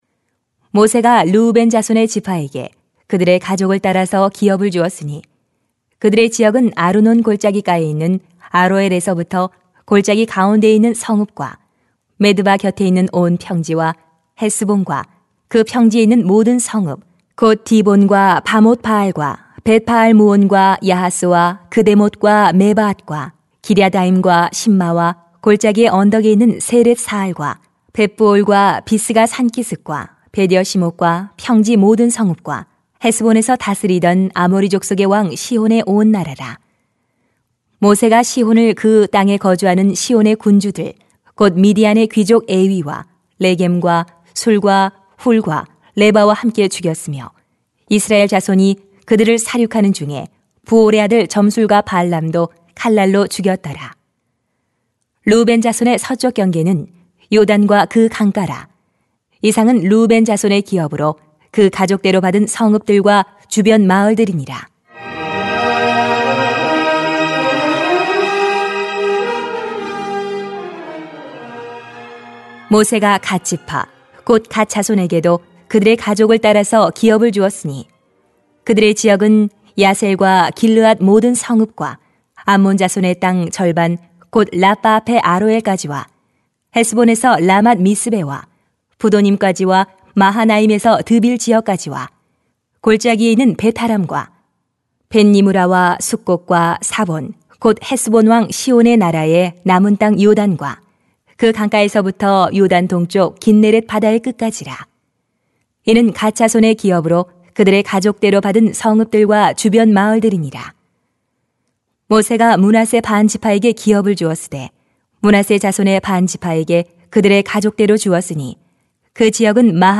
[수 13:15-33] 하나님이 가족을 귀하게 여기십니다 > 새벽기도회 | 전주제자교회